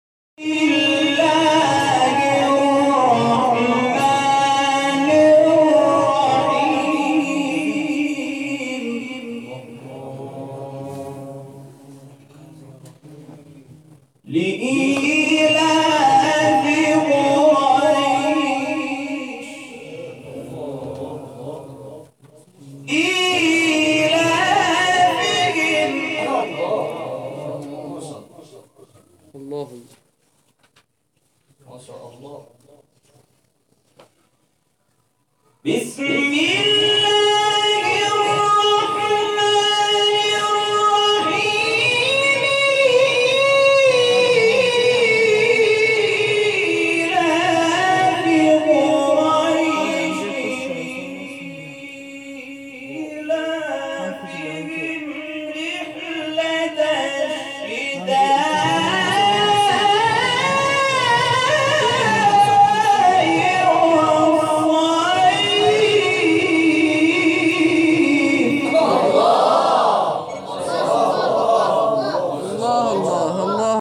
شبکه اجتماعی: مقاطعی صوتی از تلاوت قاریان ممتاز کشور را می‌شنوید.